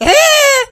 P先生只会像一只企鹅发出无意义的叫声，但是可以从中听出情绪。
Media:mrp_start_vo_04.ogg Mr. P laughs
P先生的笑声